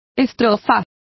Complete with pronunciation of the translation of stanzas.